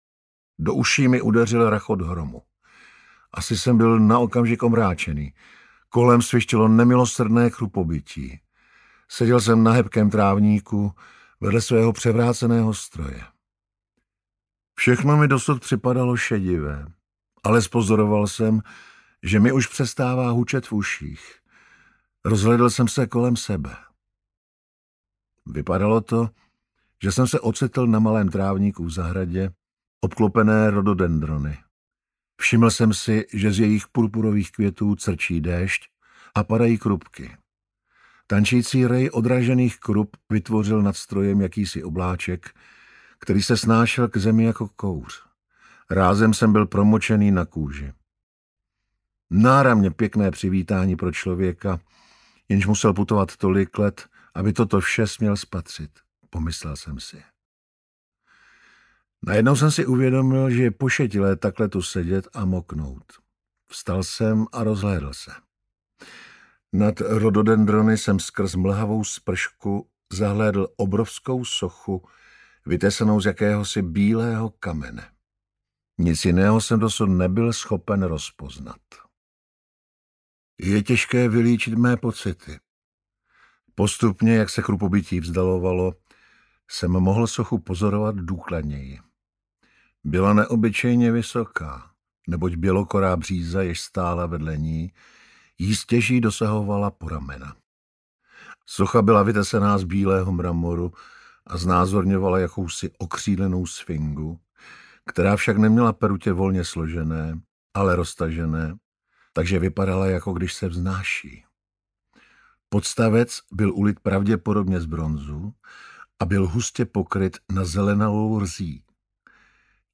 Stroj času audiokniha
Ukázka z knihy